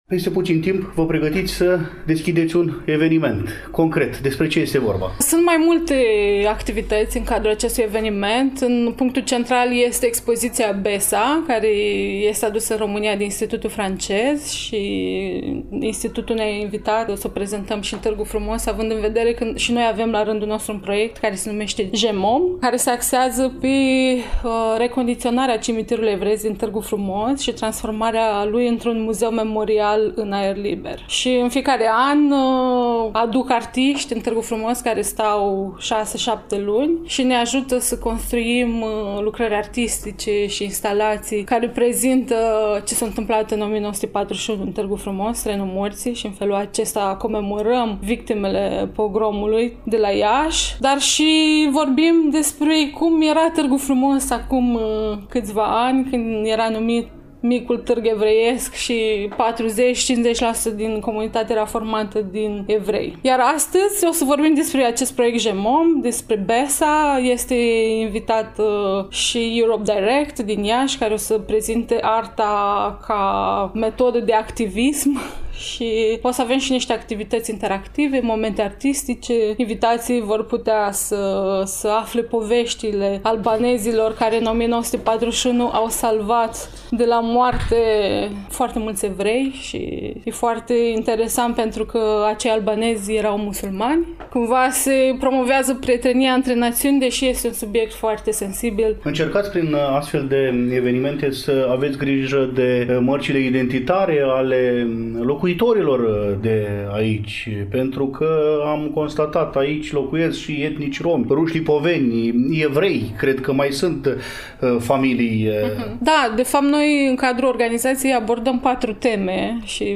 Apoi, ne purtăm pașii spre Centrul Social Multifuncțional din Târgu Frumos, acolo unde funcționează Biblioteca orașului în incinta căreia, nu demult, s-a desfășurat un frumos eveniment cu un pronunțat dialog intercultural, manifestare organizată cu prilejul Zilei Internaționale a Comemorării Victimelor Holocaustului.